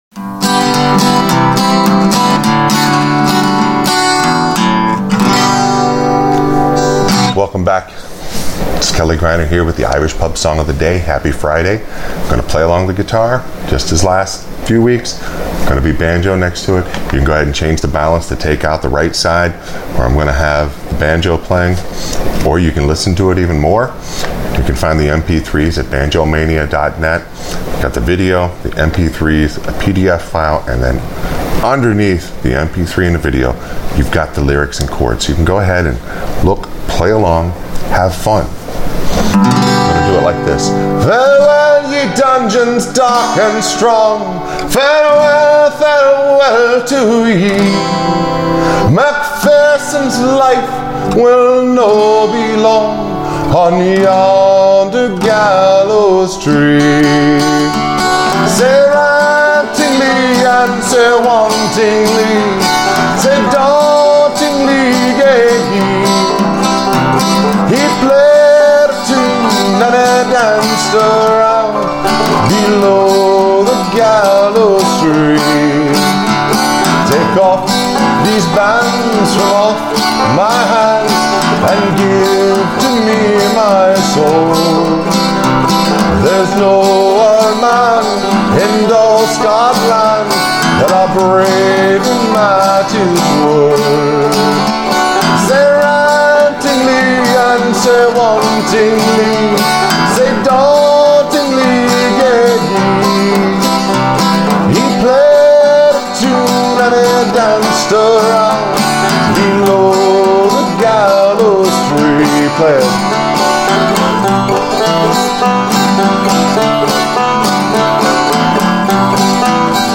Irish Pub Song Of The Day – MacPherson’s Lament – Accompaniment for Frailing Banjo
Once again on the mp3 you can change the balance to cut out most of the frailing banjo on the right. Remember that the simple melody is on the 2nd, 3rd and 4th strings.